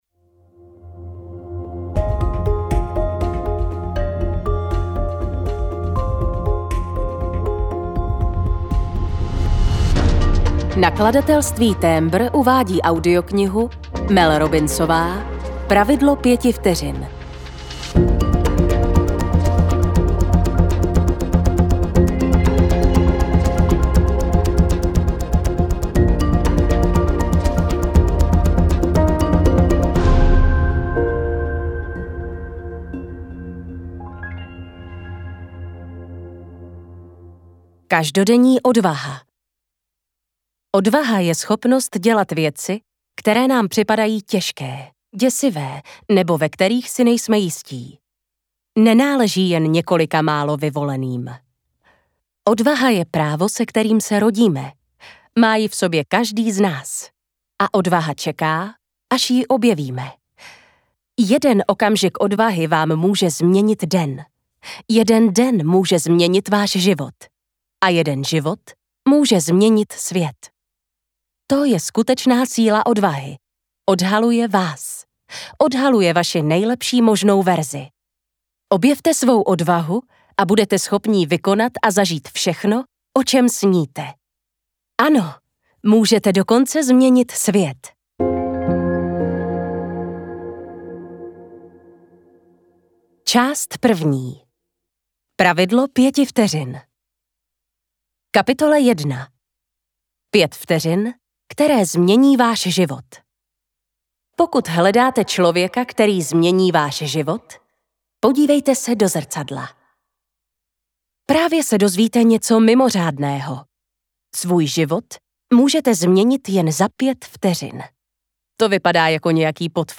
Pravidlo pěti vteřin audiokniha
Ukázka z knihy